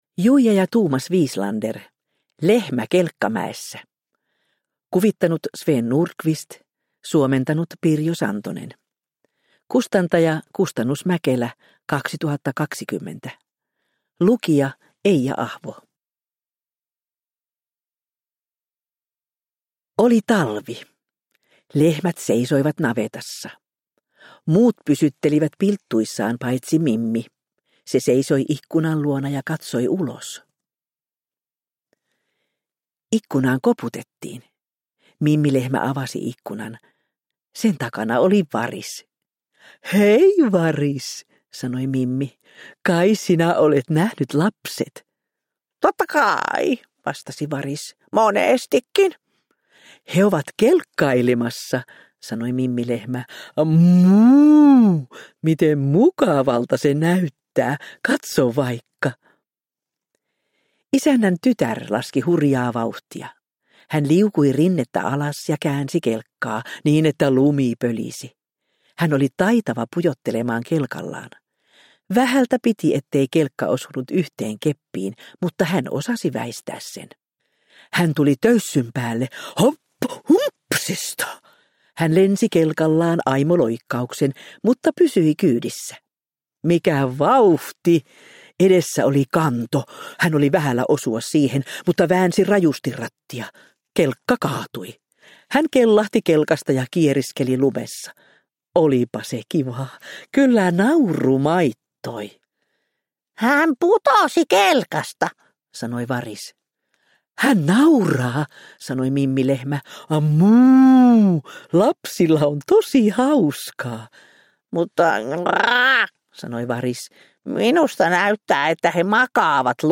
Lehmä kelkkamäessä – Ljudbok – Laddas ner